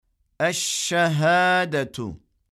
Hemze cezimli olduğunda hafif sert olarak okunur. (Sanki boğazda bir takılma olmuş gibi)